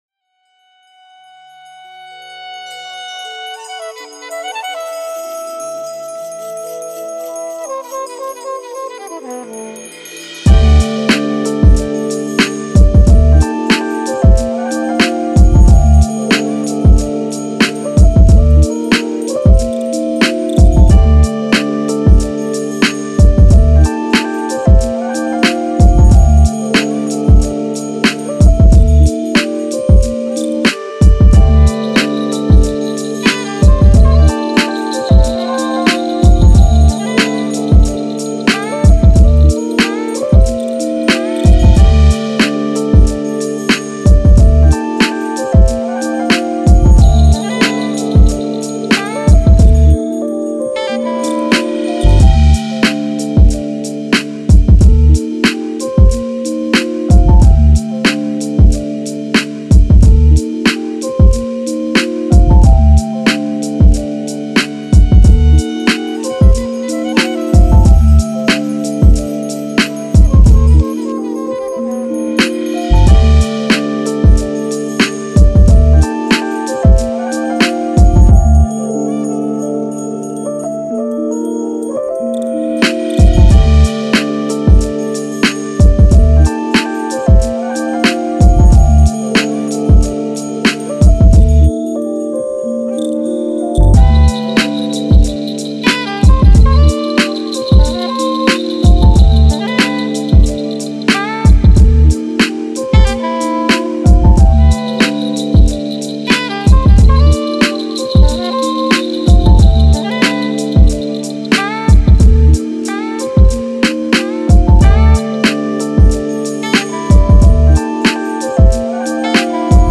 I did a lazy morning one